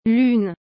A pronúncia de lune é [lyn], e aí vai o truque: esse “u” tem aquele som fechado que a gente não tem no português.
3. Deixe o “n” bem leve e nasalizado, sem soltar totalmente o som.